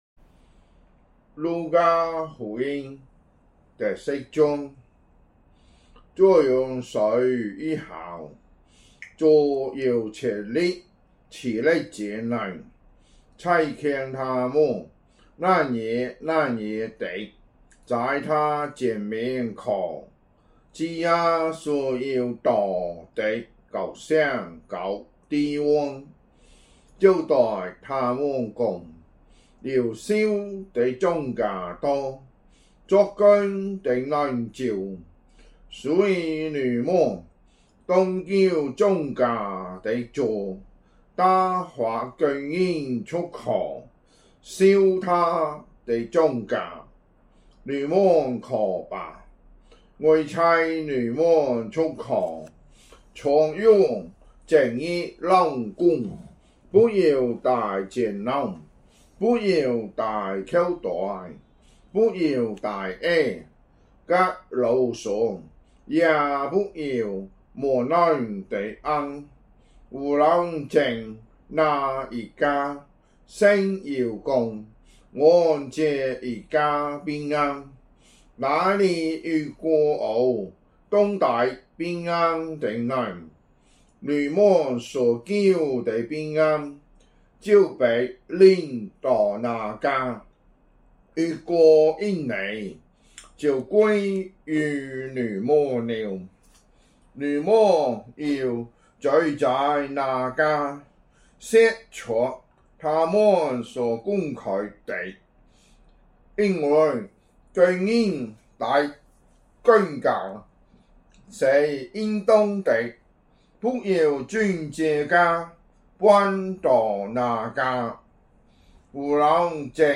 福州話有聲聖經 路加福音 10章